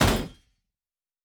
Fantasy Interface Sounds
Weapon UI 11.wav